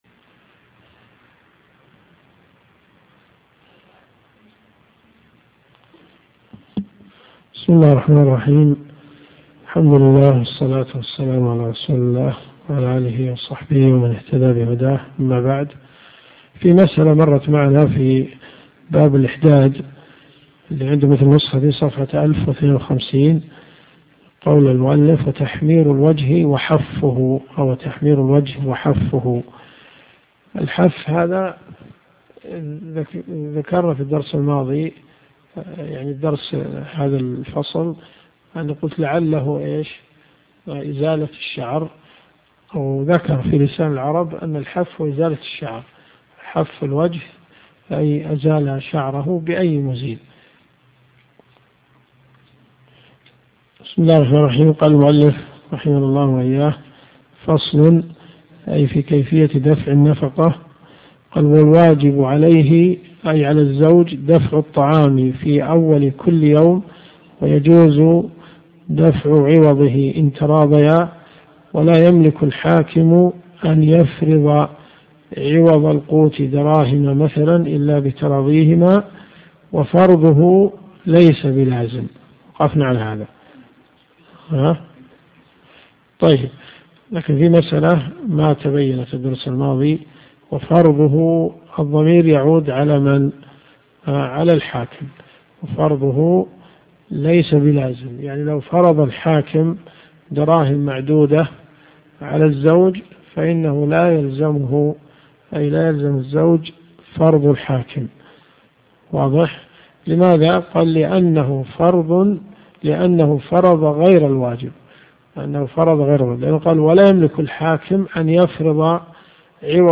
الرئيسية الكتب المسموعة [ قسم الفقه ] > منار السبيل .